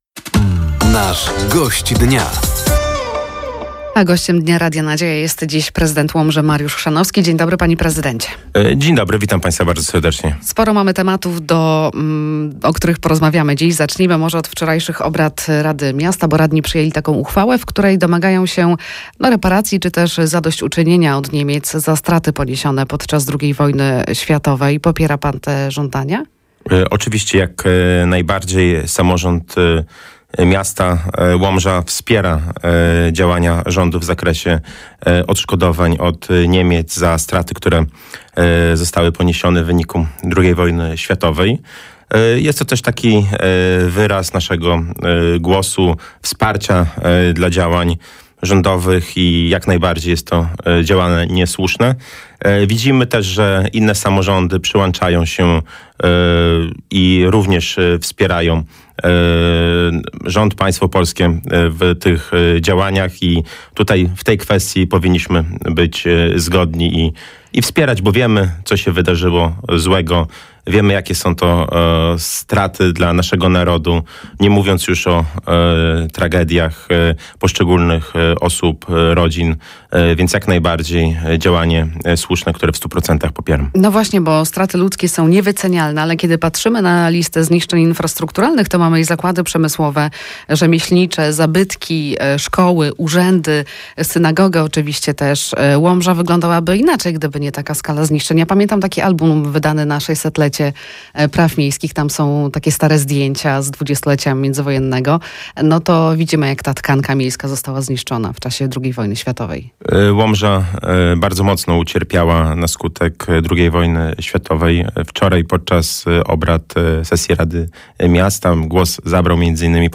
Gościem Dnia Radia Nadzieja był prezydent Łomży, Mariusz Chrzanowski. Tematem rozmowy były między innymi reparacje od Niemiec, Budżet Obywatelski i współpraca samorządu łomżyńskiego z rządem.